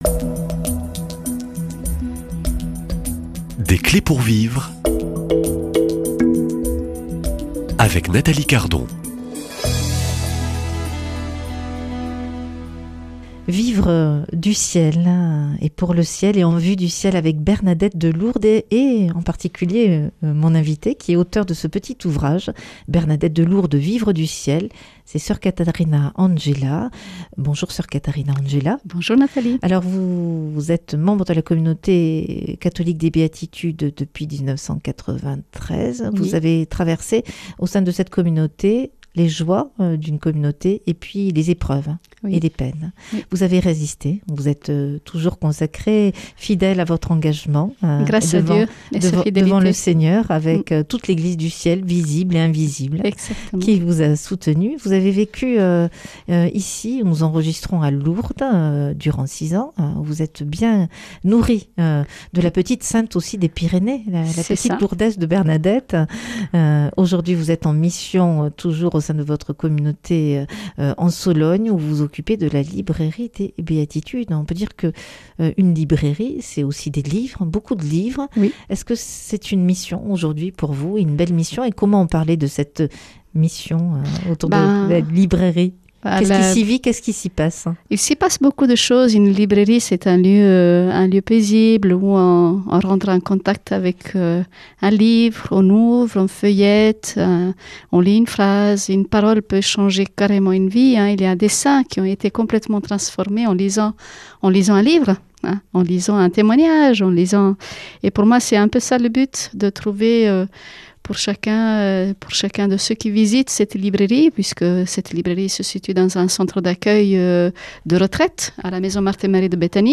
Témoignages \ Des clés pour vivre